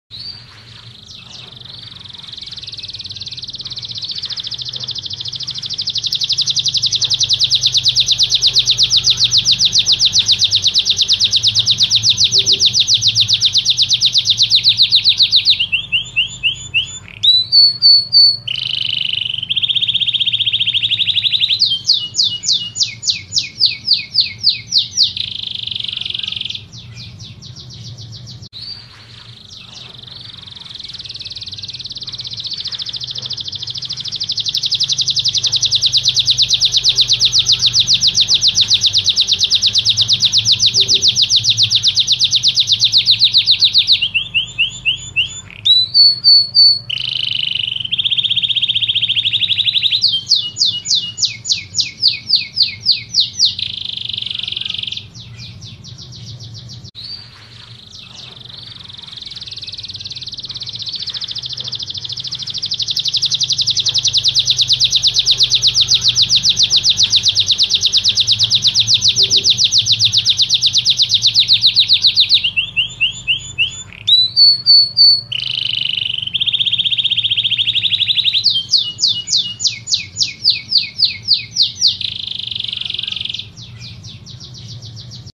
Burung jenis ini sangat ramai diperlombakan, karena suaranya dapat melengking apabila dirawat dengan baik dan benar.